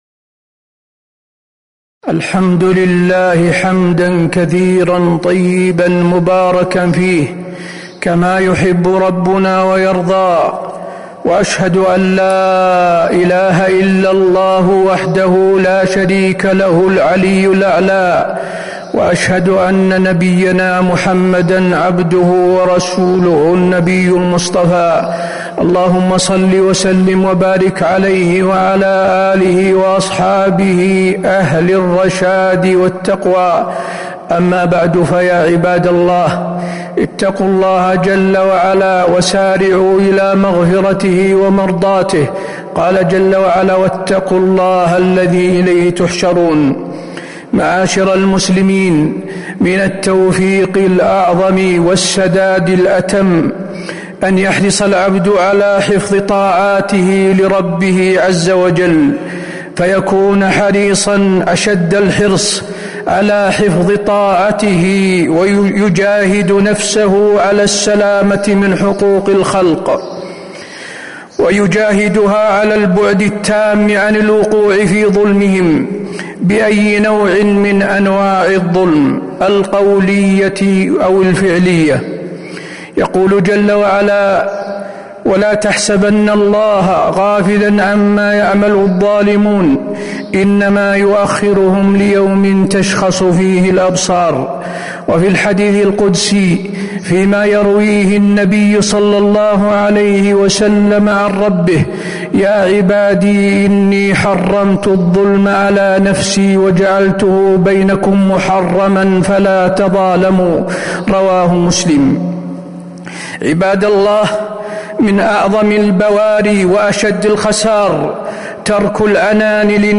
تاريخ النشر ٢٧ شوال ١٤٤٦ هـ المكان: المسجد النبوي الشيخ: فضيلة الشيخ د. حسين بن عبدالعزيز آل الشيخ فضيلة الشيخ د. حسين بن عبدالعزيز آل الشيخ التحذير من ظلم الناس وأكل حقوقهم